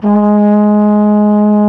TENORHRN G#1.wav